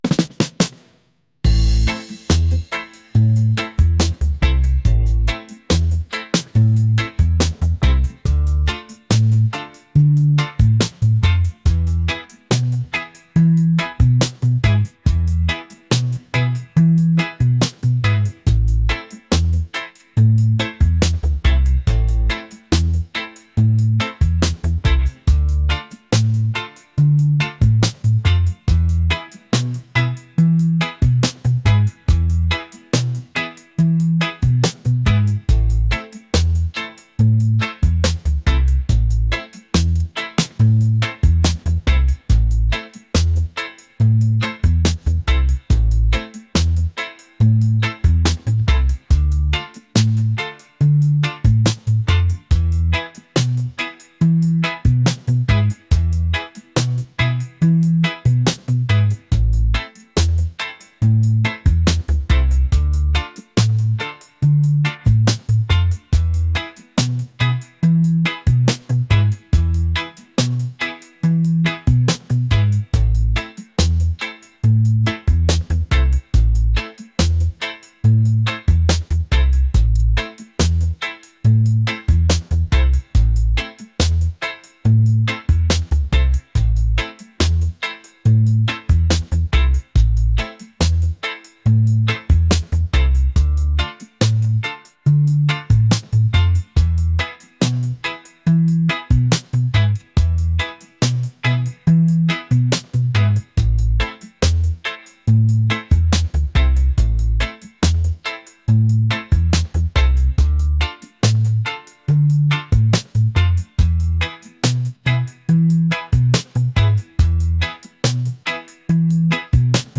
groovy | laid-back | reggae